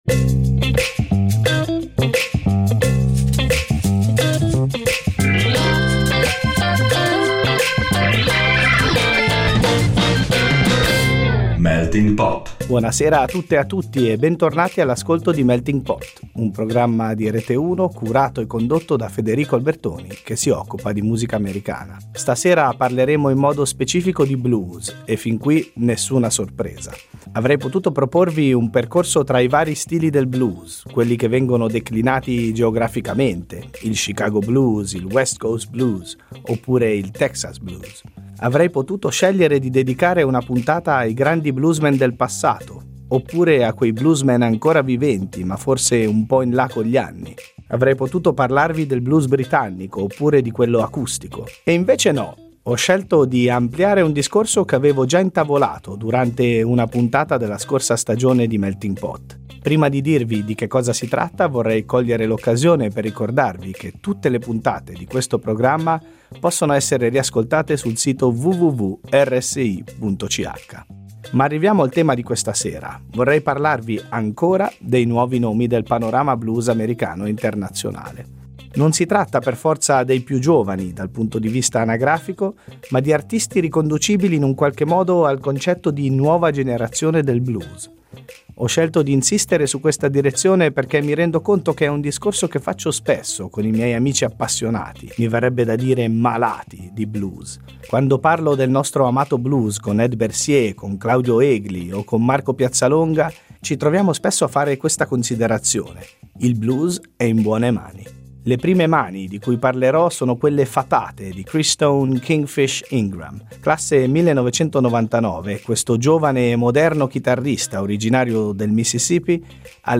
La nona puntata di Melting Pot è dedicata principalmente alla nuova generazione del blues, tra giovani talenti e artisti di seconda generazione che uniscono virtuosismo, passione e rispetto per le radici del genere a influenze contemporanee.